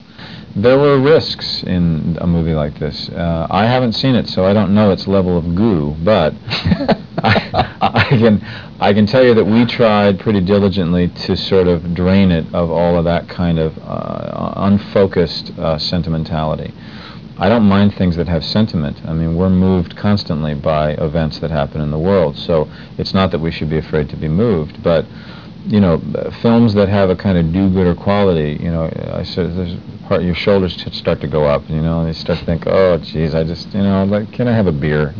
Kevin talks about how PIF rises above "goo."